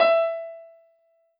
piano-ff-56.wav